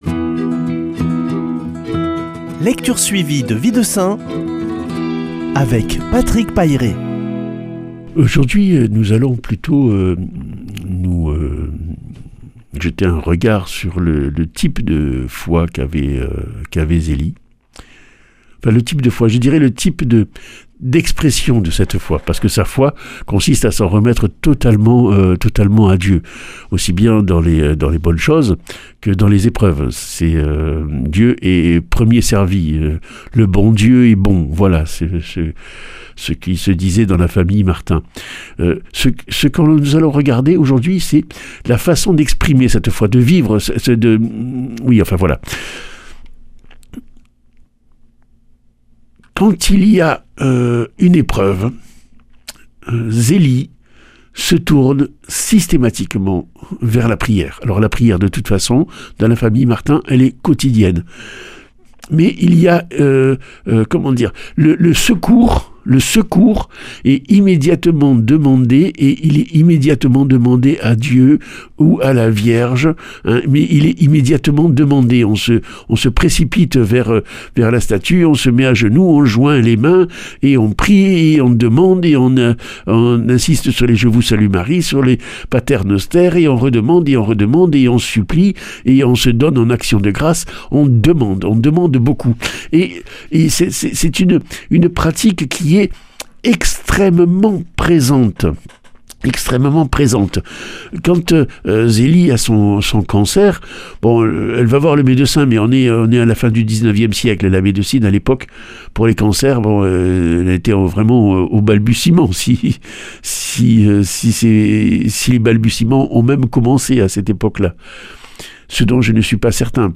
Lecture suivie de la vie des saints